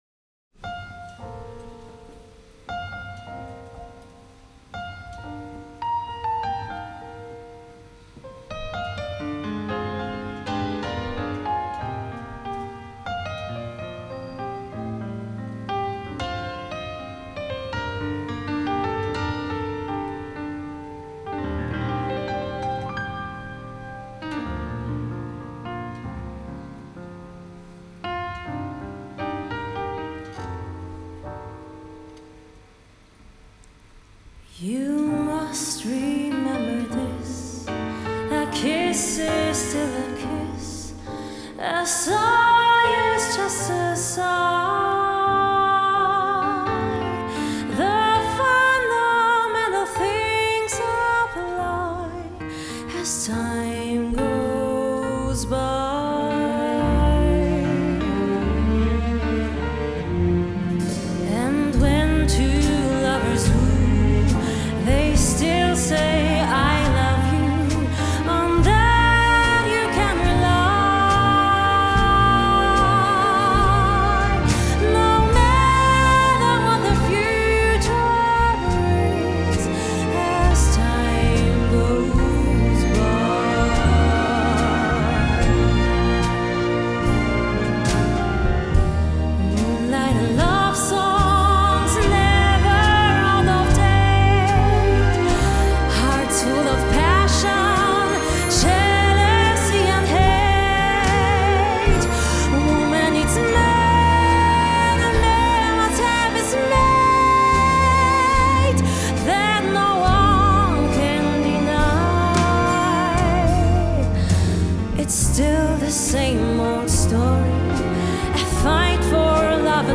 Live-Performance